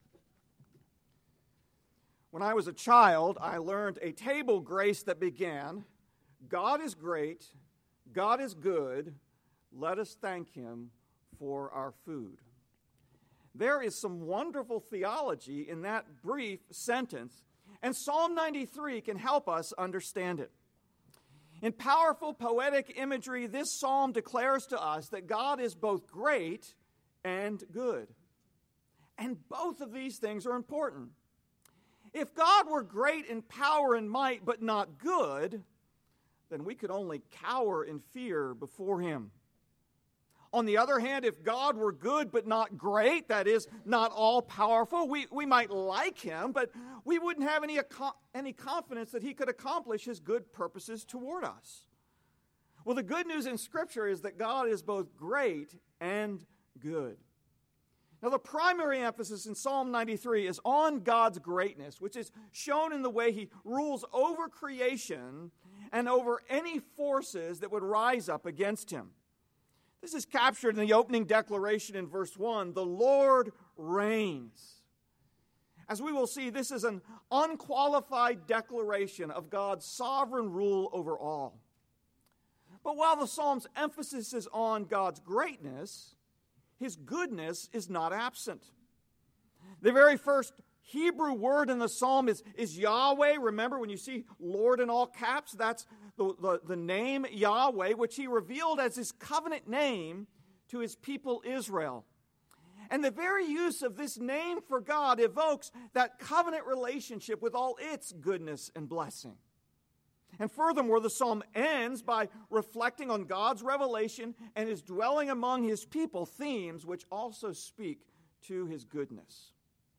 Evening Sermon